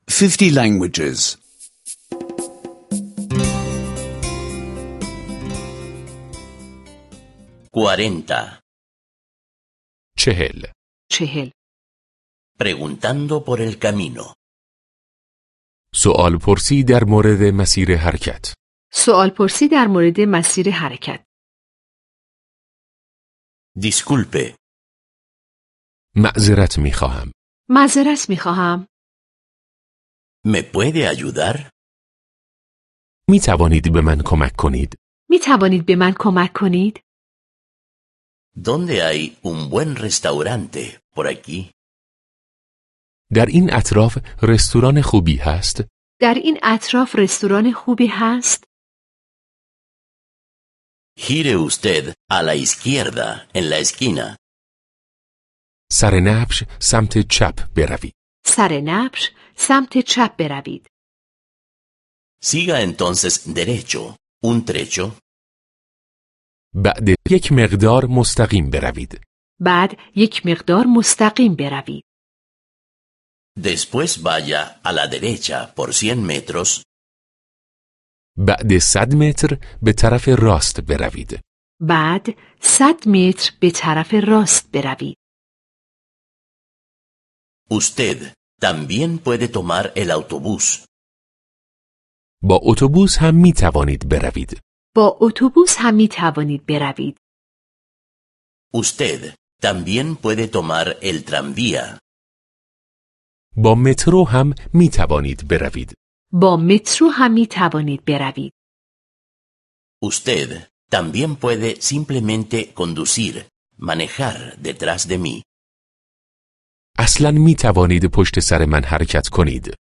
Curso de audio de persa (escuchar en línea)